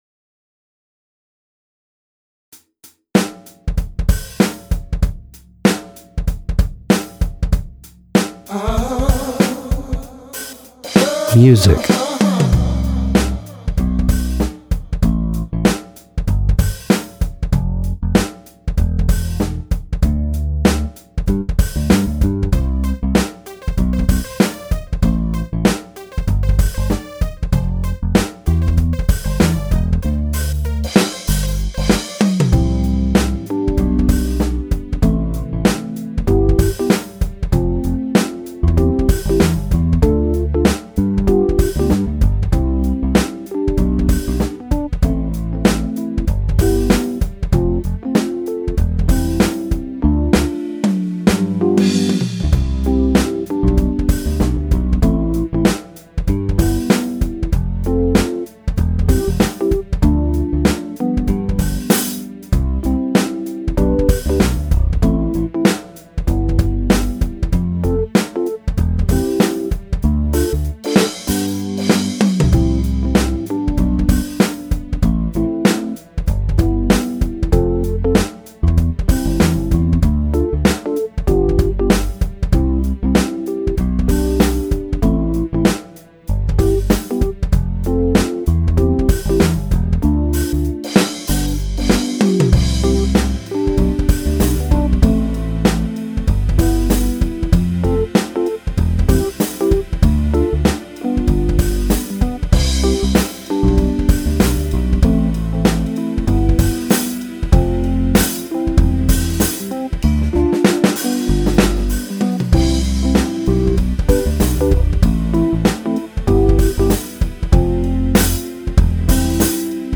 伴奏
Quartetto Sax